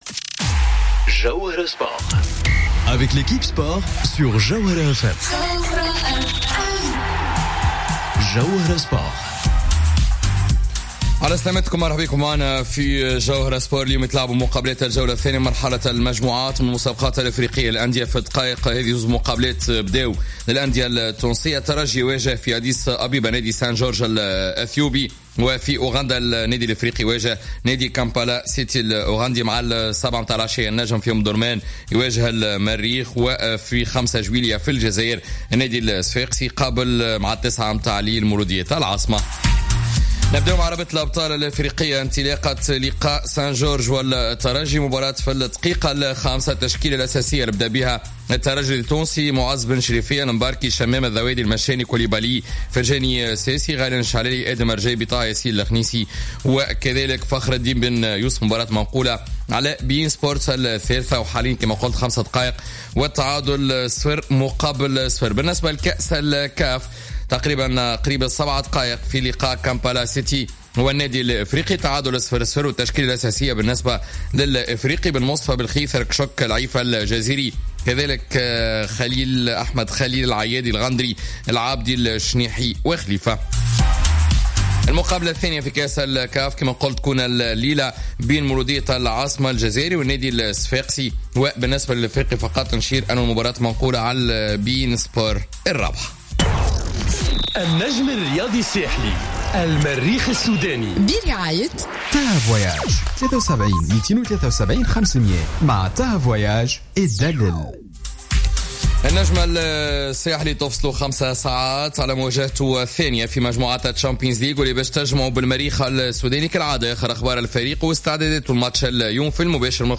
متابعة مباشرة لمقابتي الترجي و الإفريقي في المسابقات الإفريقية